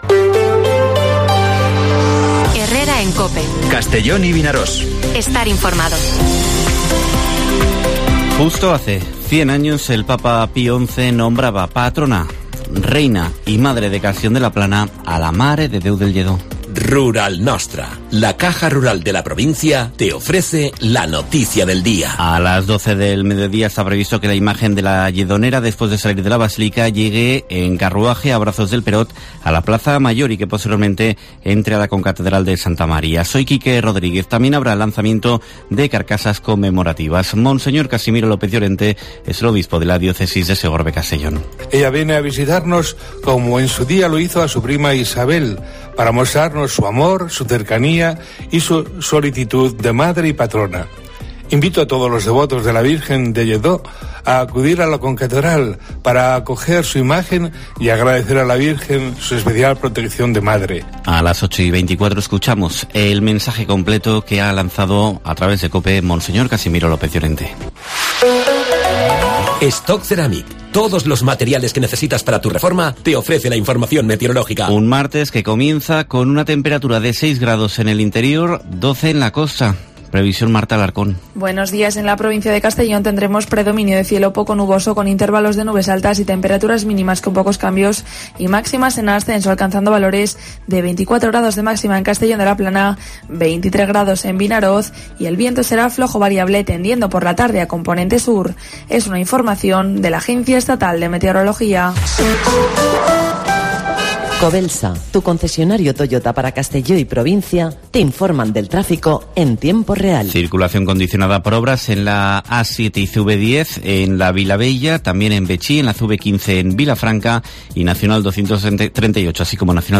Informativo Herrera en COPE en la provincia de Castellón (08/1/2022)